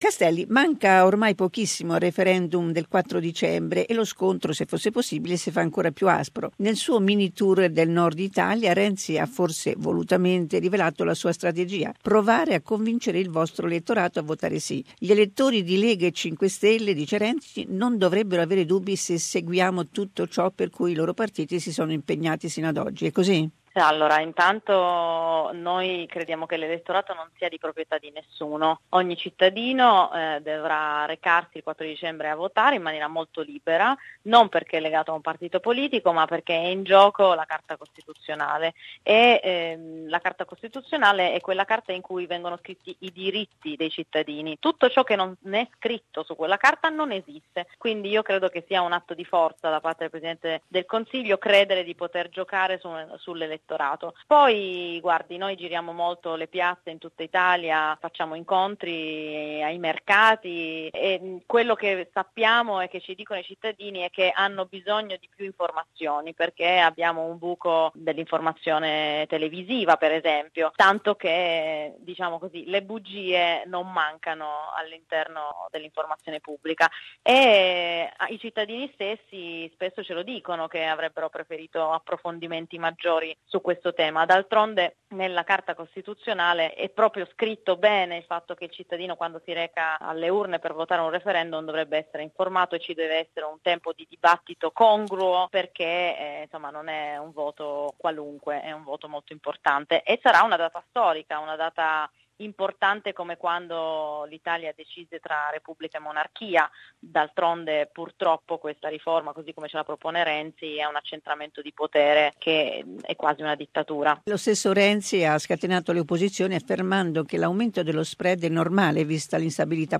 Con l'esponente del M5S, Laura Castelli, abbiamo parlato della strategia adottata da Matteo Renzi per tentare di convincere l'elettorato dei pentastellati a votare "sì" al referendum costituzionale del 4 dicembre prossimo.